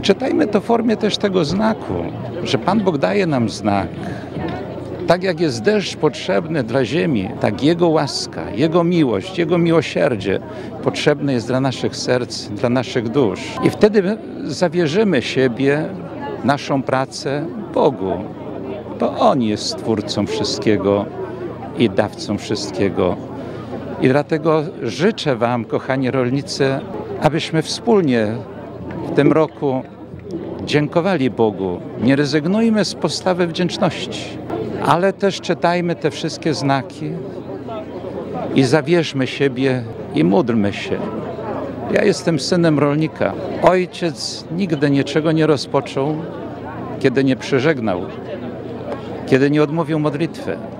Wojewódzkie dożynki odbyły się dziś w pokamedulskim klasztorze nad Wigrami.
Natomiast biskup ełcki Jerzy Mazur prosił rolników, aby trudną sytuację traktowali jako znak i zawierzyli swoją pracę Bogu.